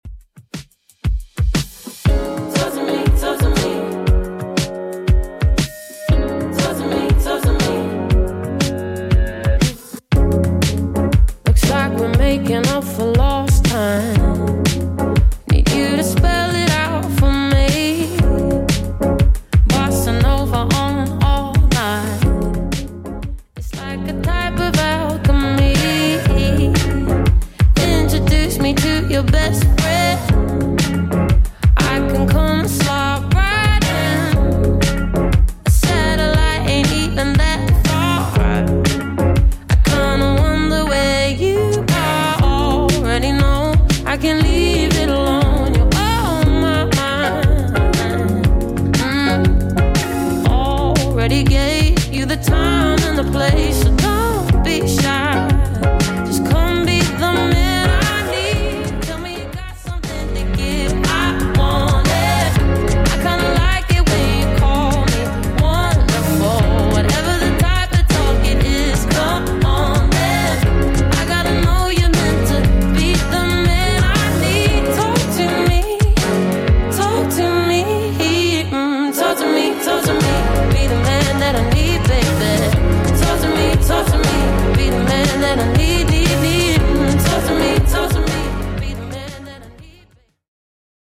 Genre: 80's Version: Clean BPM: 126